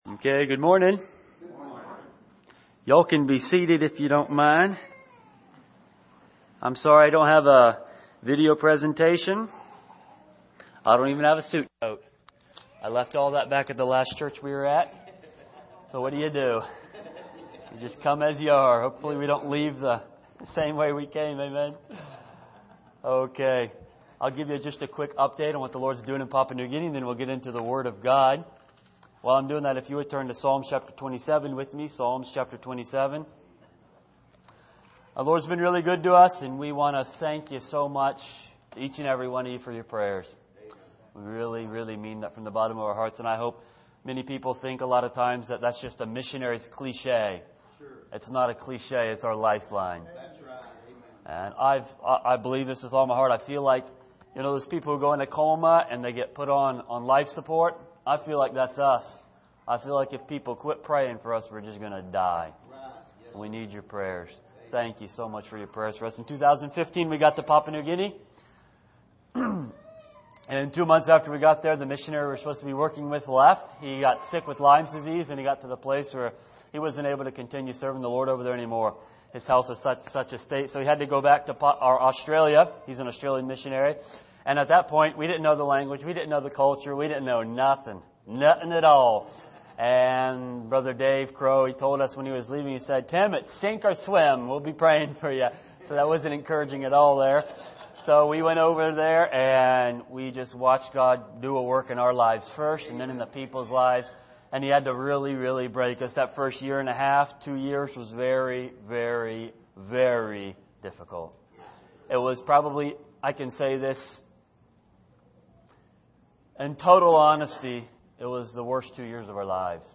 Miscellaneous Passage: Psalm 27:4 Service: Sunday Morning What Is Your Desire?